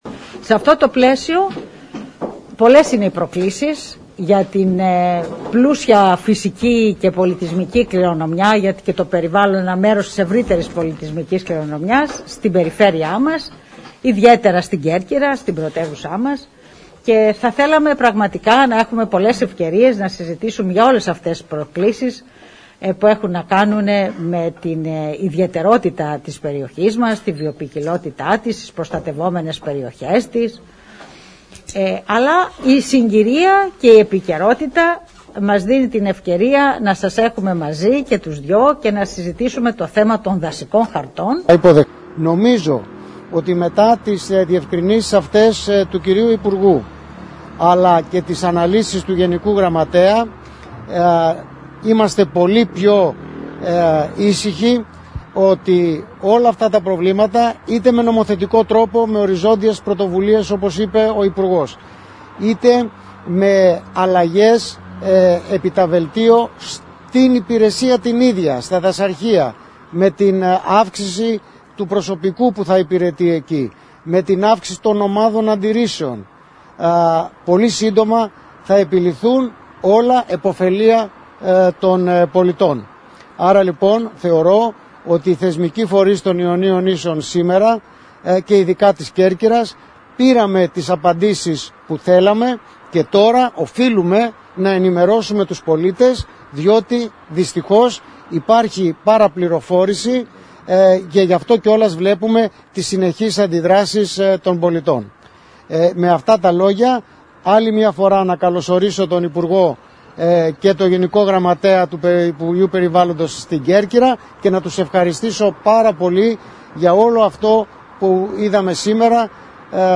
Ακολουθούν αποσπάσματα από τις δηλώσεις τους.